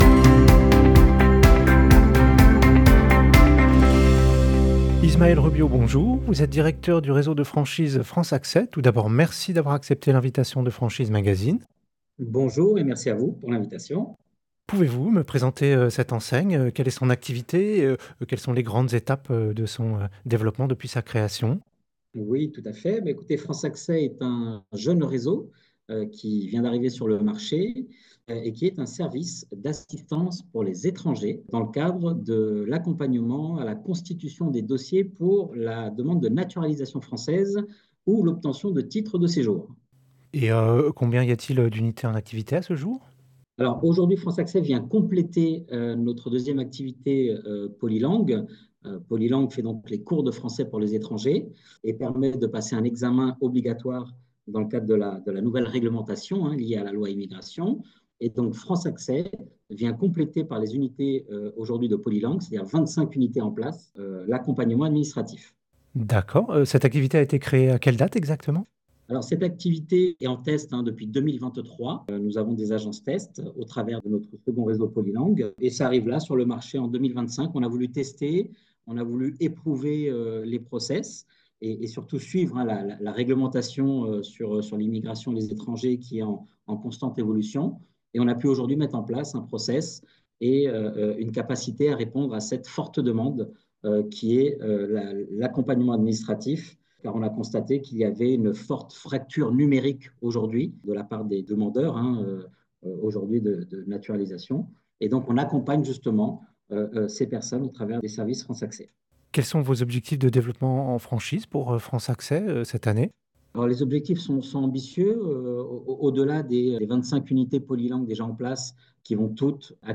Au micro du podcast Franchise Magazine : la Franchise France Acces - Écoutez l'interview